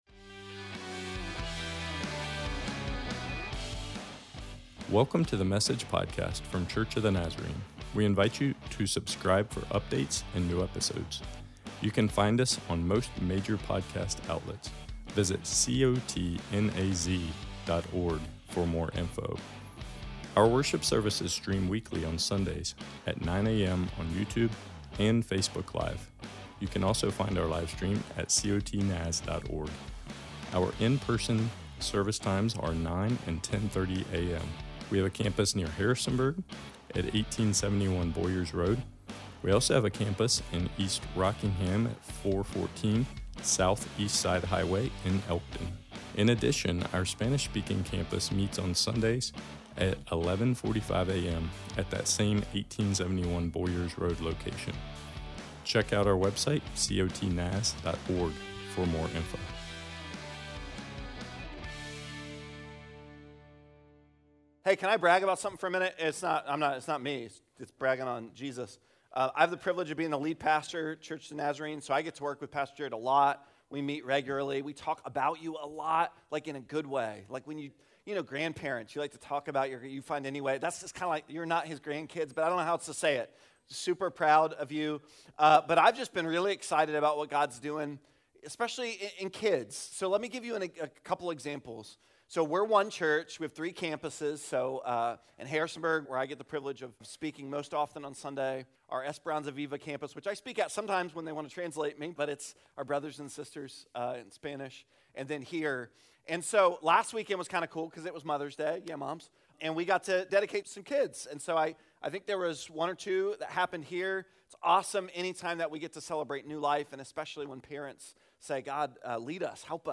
Church of the Nazarene East Rock Questions Jesus Asked – Do You Want to Get Well?When you’re ready, He is. Today we continue in our 4-part teaching series “Questions Jesus Asked” Often, we ar…